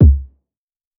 KICK - i bet dilla would've loved dis kick.wav